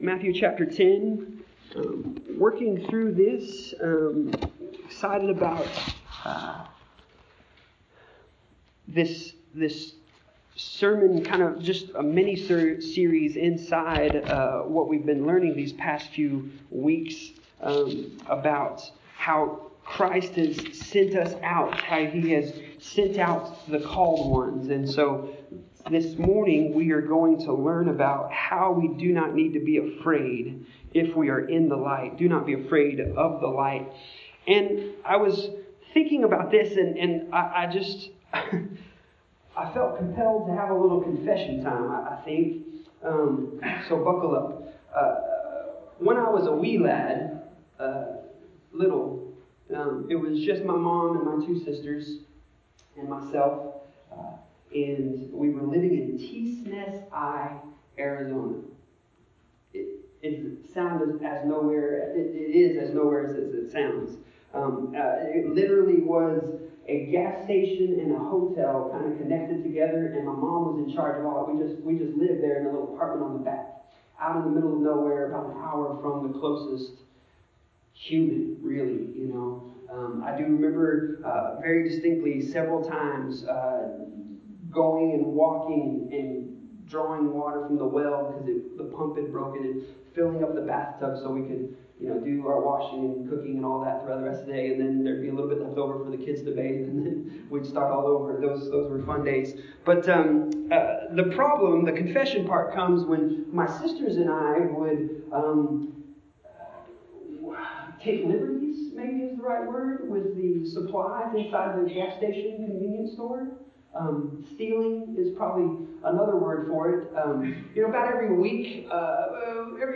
Passage: Matthew 10:26-33 Service Type: Sunday Morning